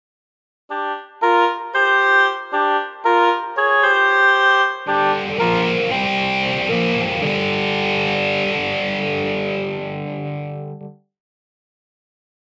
Oboe übern Amp mit Distortionpedal zu spielen muss echt gut kommen. Also, zwei Obisten, mit Powerchordabstand, natürlich.